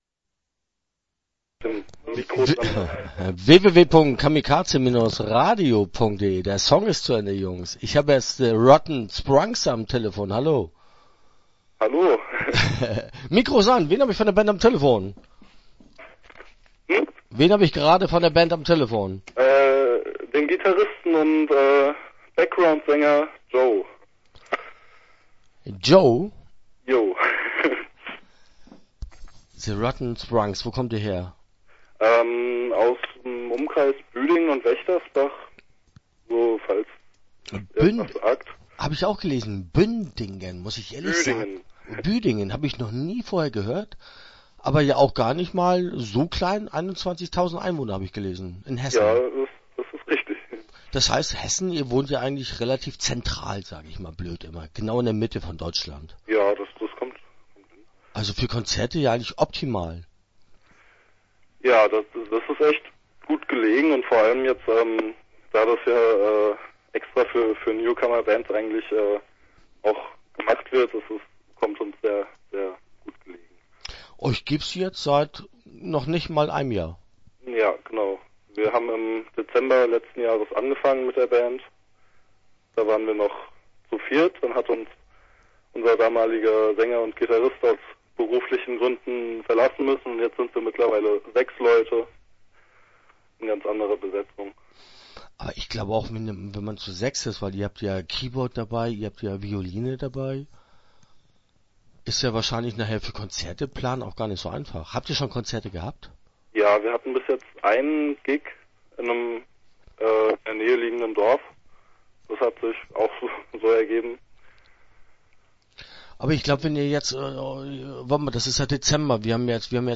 Start » Interviews » The Rotten Sprunks